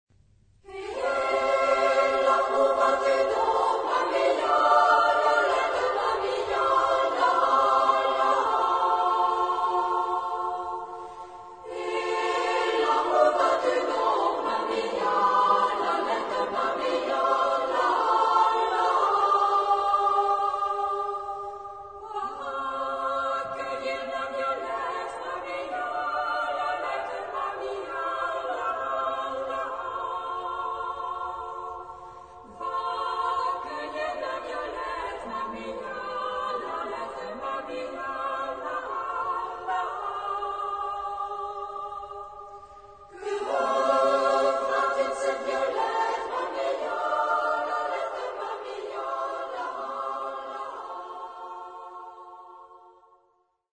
Genre-Style-Forme : Populaire ; Enfants ; Chanson ; Profane
Type de choeur : SSAA  (4 voix égales de femmes )
Origine : Bourbonnais (France)